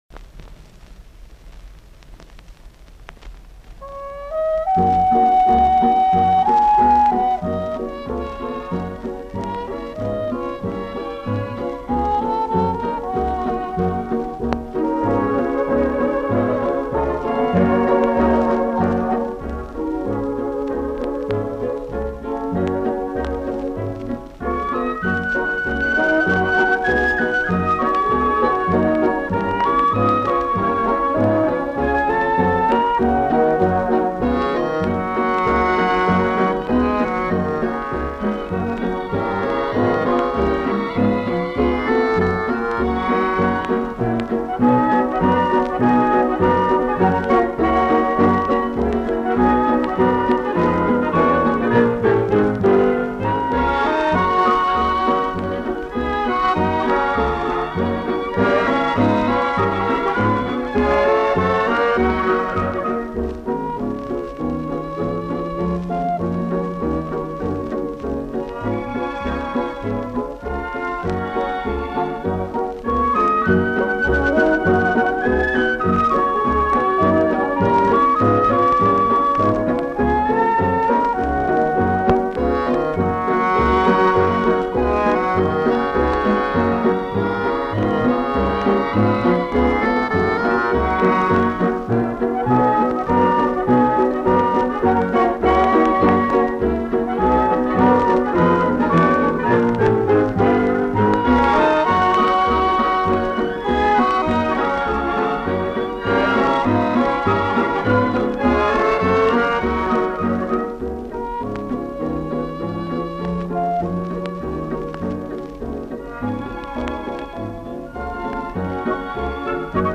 фокстрот